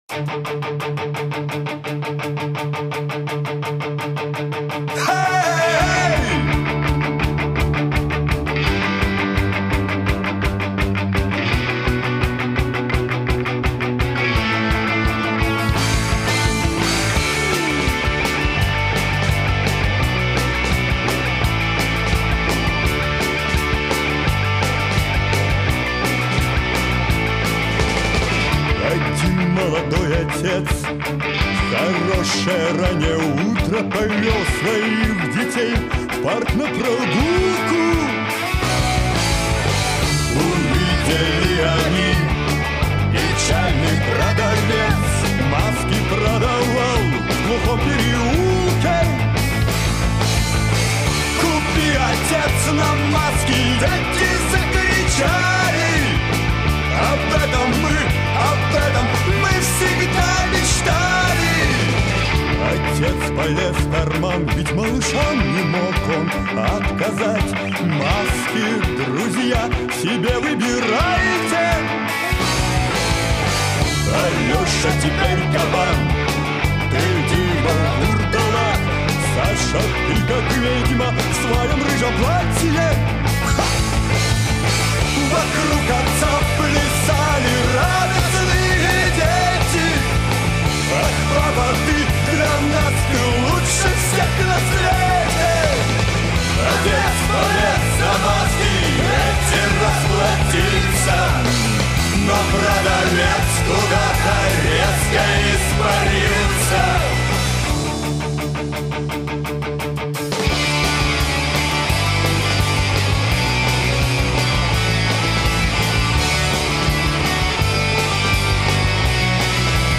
Рок [115]